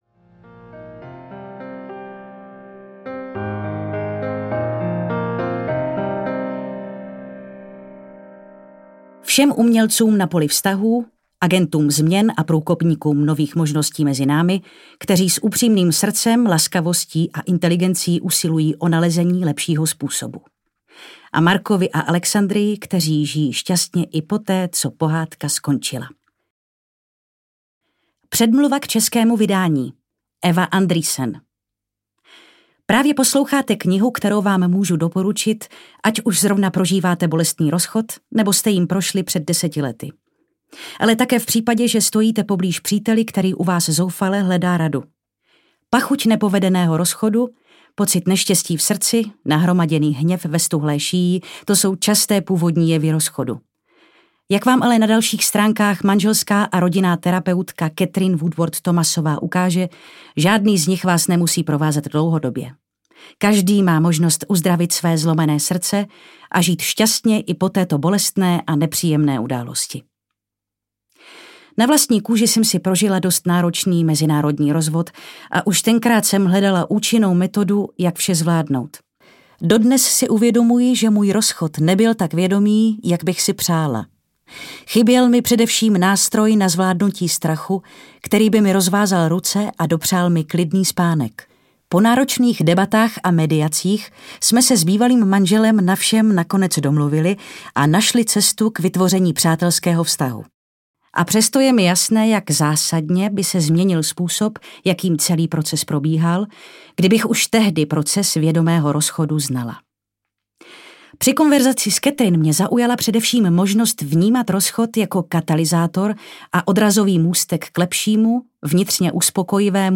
Rozcházení audiokniha
Ukázka z knihy
rozchazeni-audiokniha